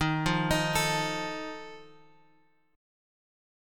D# Suspended 2nd Flat 5th